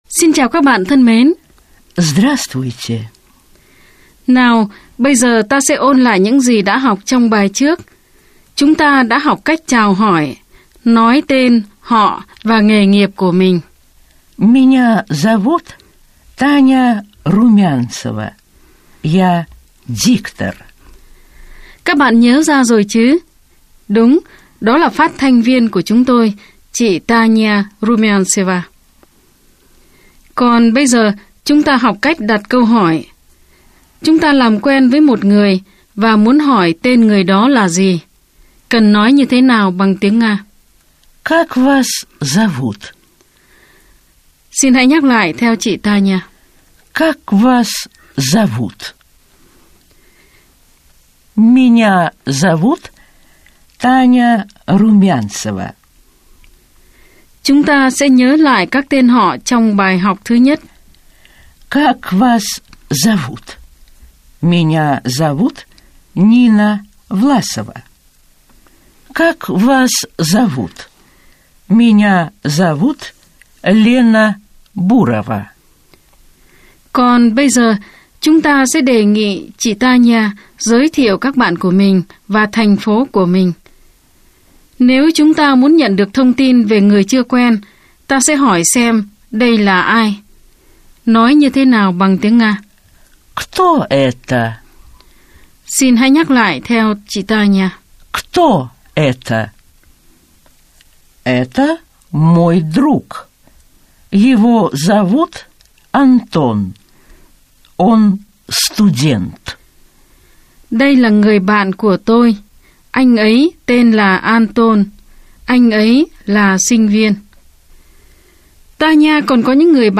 Bài 2 – Bài giảng tiếng Nga
Nguồn: Chuyên mục “Chúng ta học tiếng Nga” đài phát thanh  Sputnik